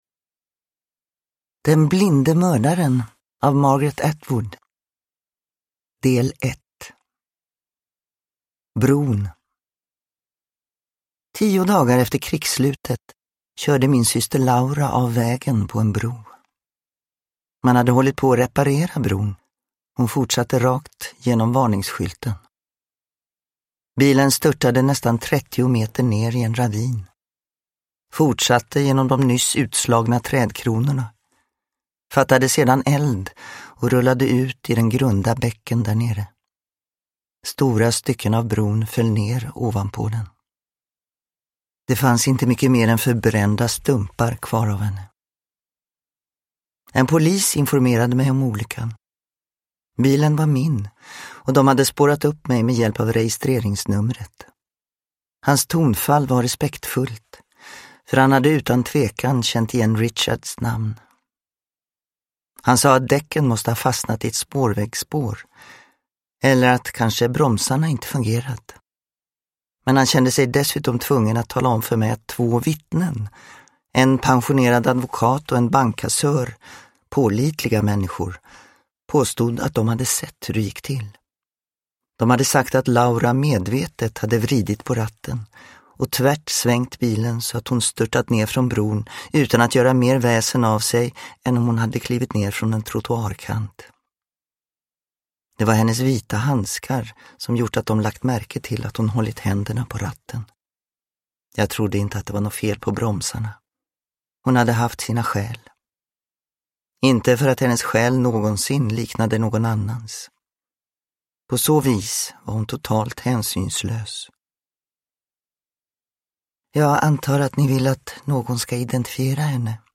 Den blinde mördaren – Ljudbok – Laddas ner
Uppläsare: Gunnel Fred